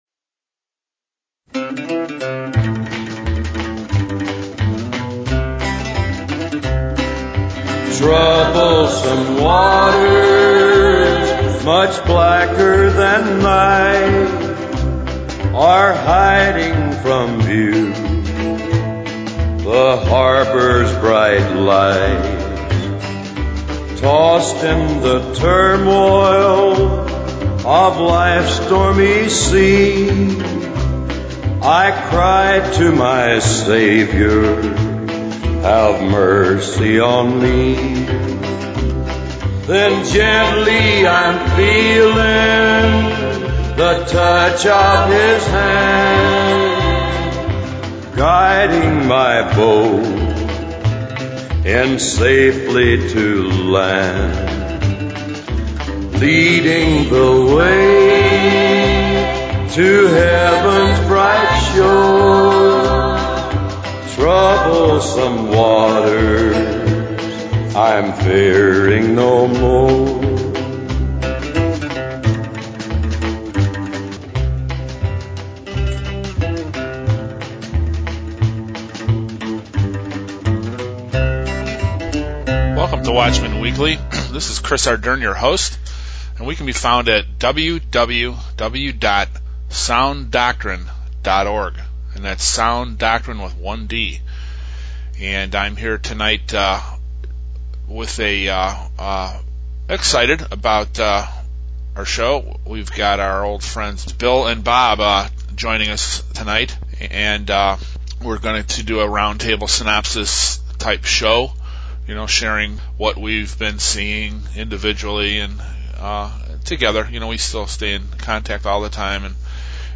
Watchmen Weekly: Roundtable Synopsis Show
The Watchmen come together for a round table presentation sharing from the heart